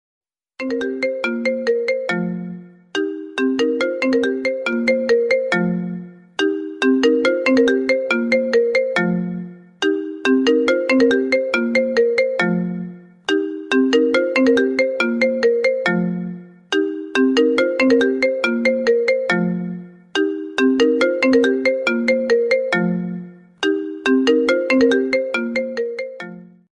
Ringtones